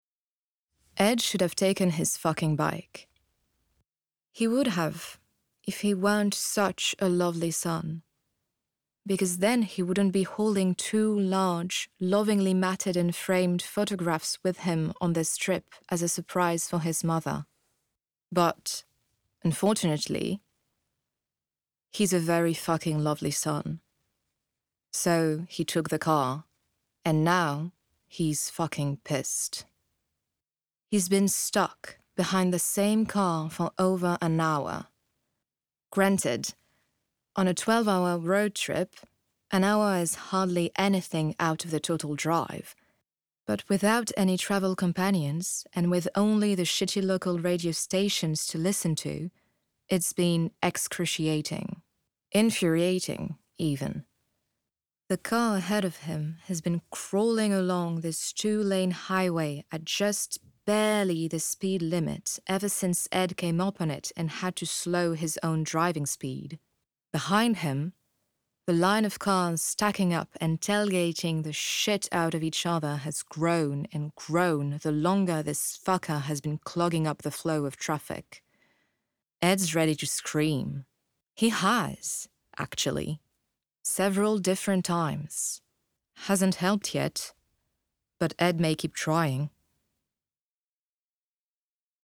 English voice demo - Audio book
8 - 50 ans - Mezzo-soprano